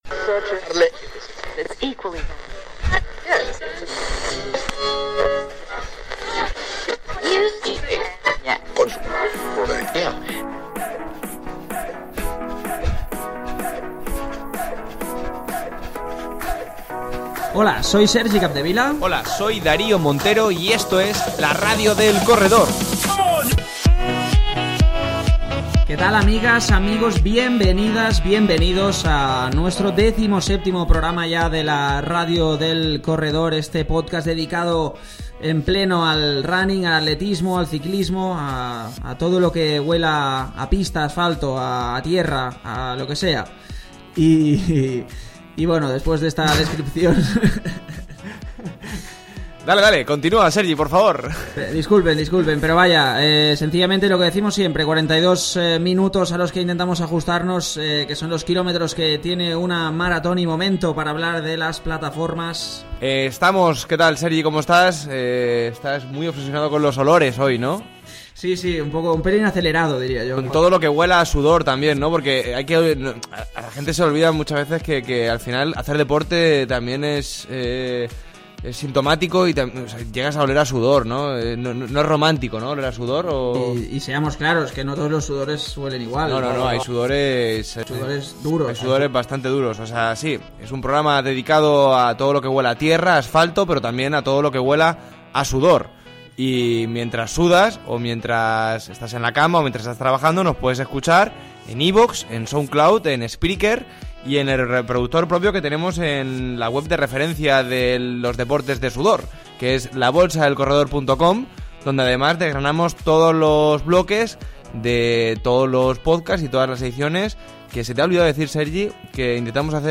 Una decimoséptima edición que abrimos con una entrevista de ‘kilates’ con el ‘trail runner’ Luis Alberto Hernando, que se ha proclamado recientemente campeón del mundo en Penyagolosa junto con el Equipo Nacional.